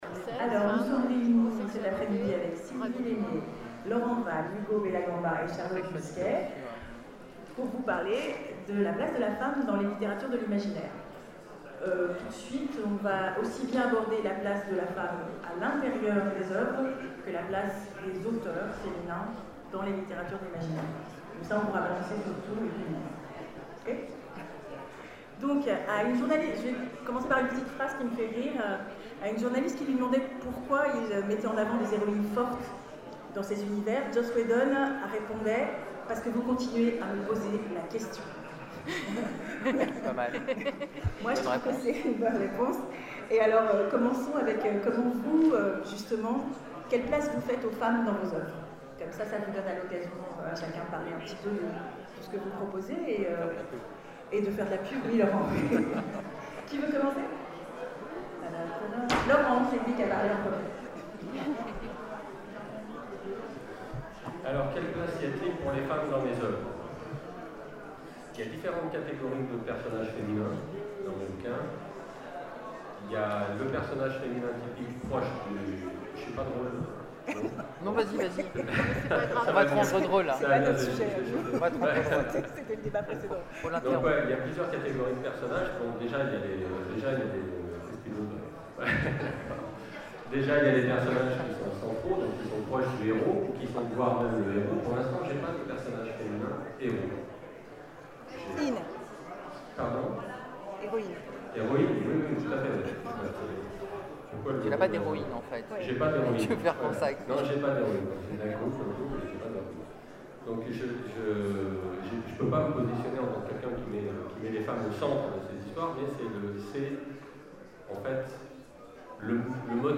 Convention 2012 : Conférence La place de la femme dans la littérature de l'imaginaire